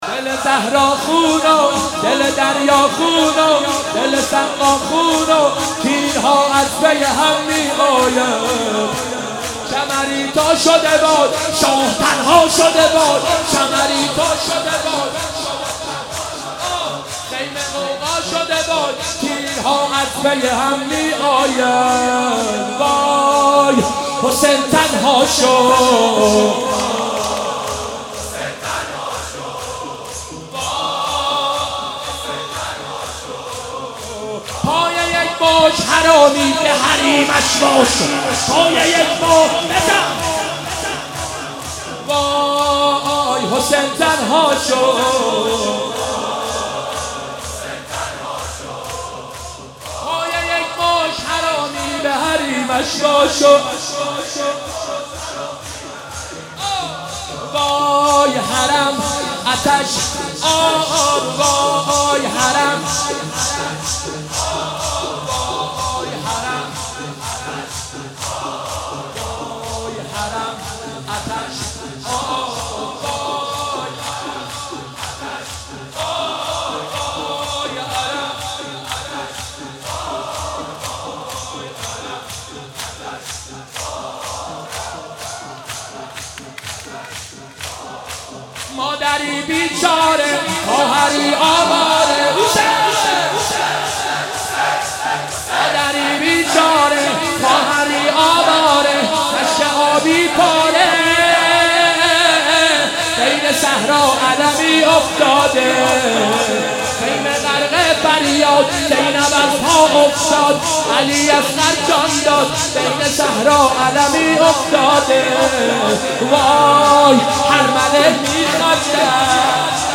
شب تاسوعا محرم95/هیئت خادم الرضا (ع) قم
شور/دل زهرا(س)